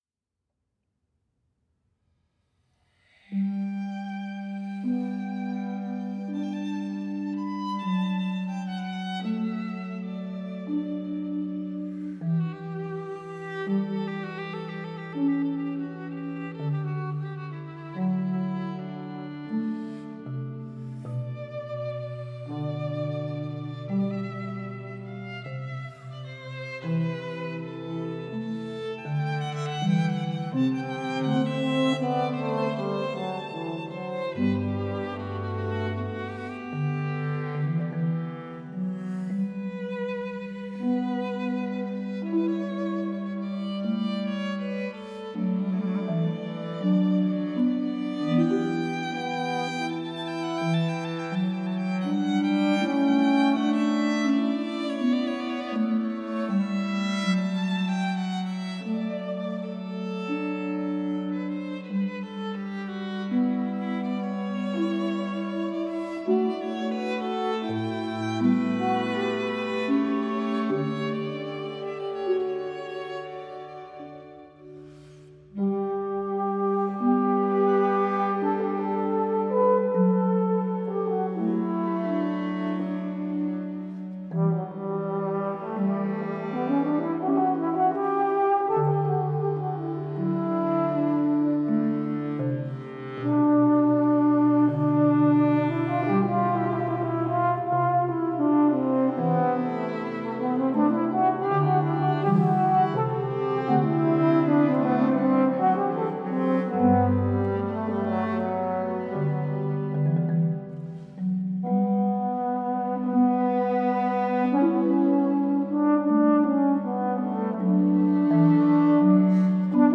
French horn
violin
marimba
bass clarinet